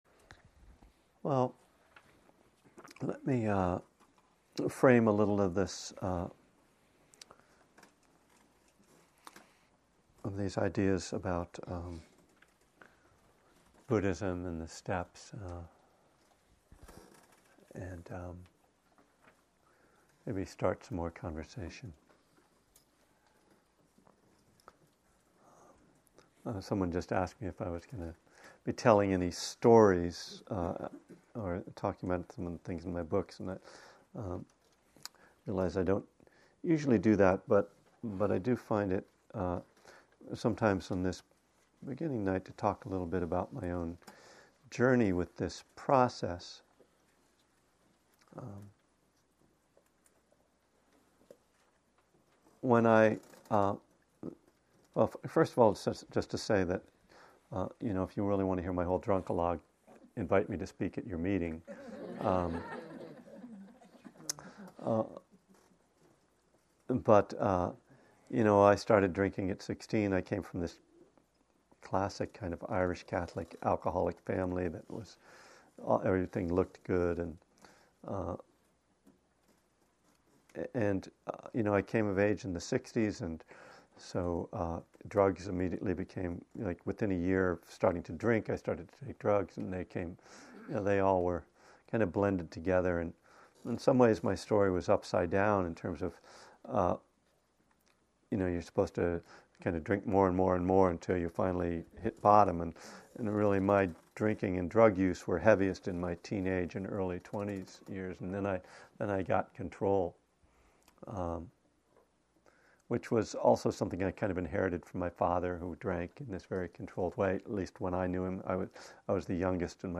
In this talk from 2011, I tell some of my drunkalogue, then start to talk about powerlessness and Step 1. Topics include, karma, triggers, craving, the Four Noble Truths, Mindfulness, and the Middle Way. Connecting Buddhism and the Twelve Steps.